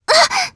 Frey-Vox_Damage_jp_01.wav